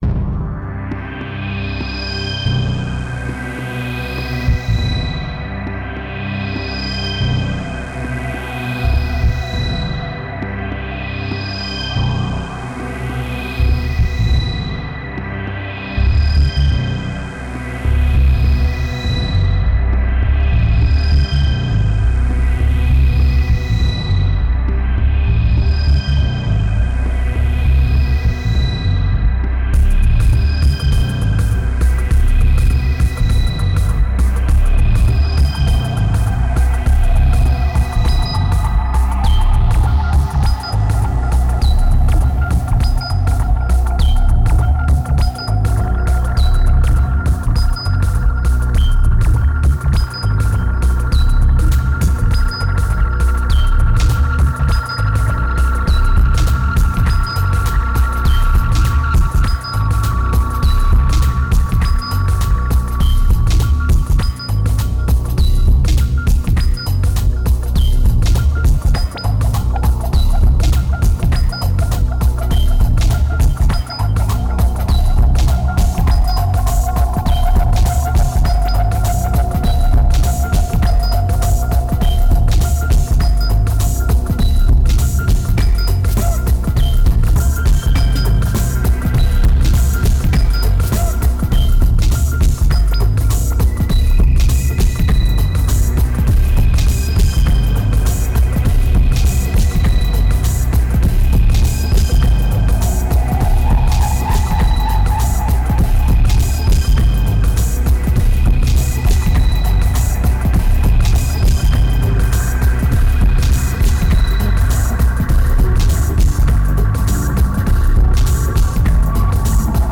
2281📈 - -16%🤔 - 101BPM🔊 - 2010-06-22📅 - -228🌟